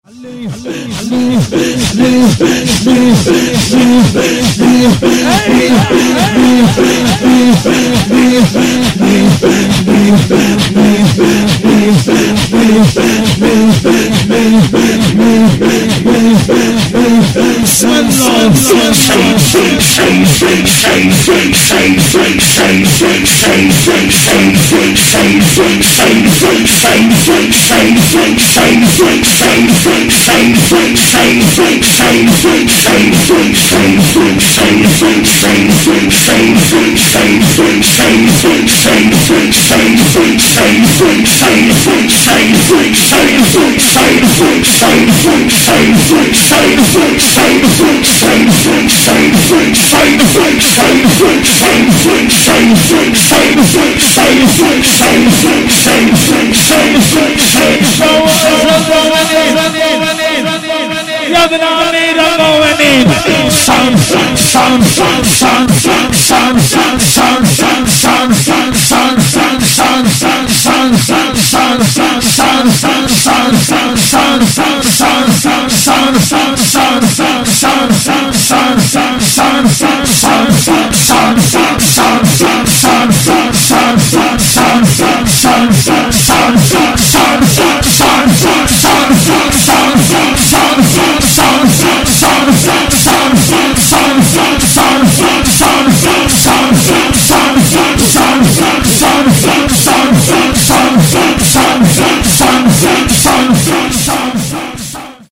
جشن ولادت حضرت زهرا(س)- جمعه 18 اسفند